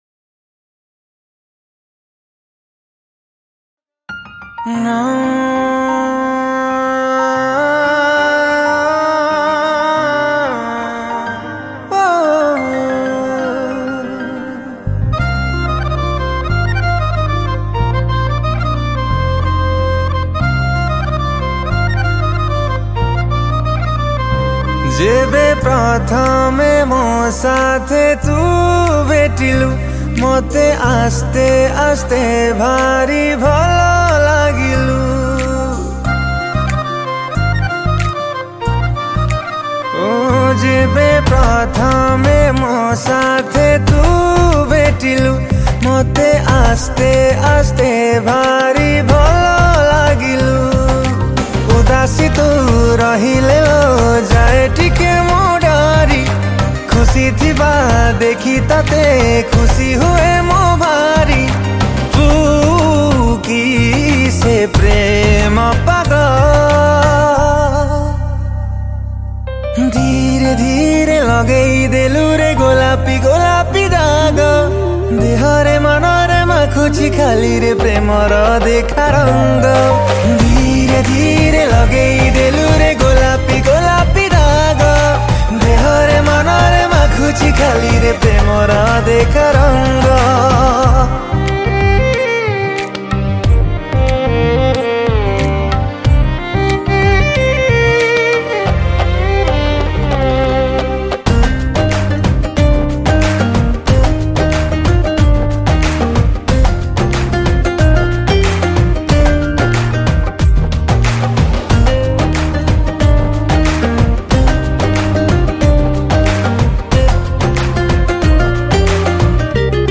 Recorded At : Smruti Studio, BBSR